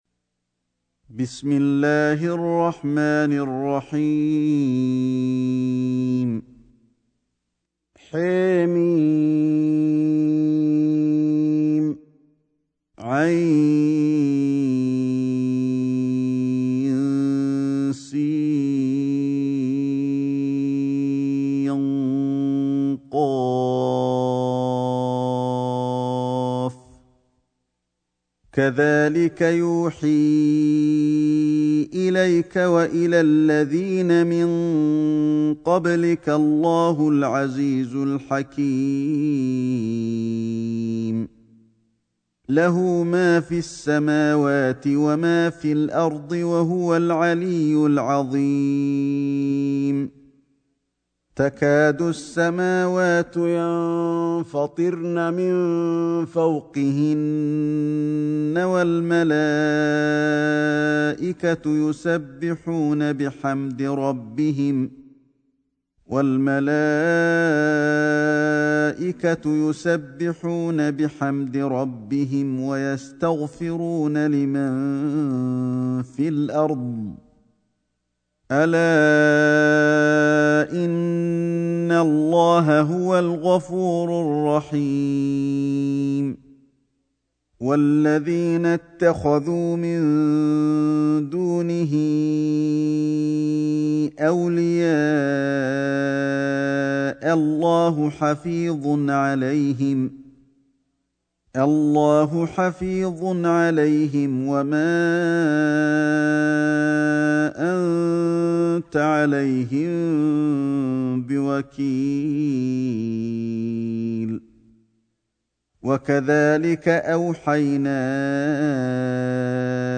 سورة الشورى > مصحف الشيخ علي الحذيفي ( رواية شعبة عن عاصم ) > المصحف - تلاوات الحرمين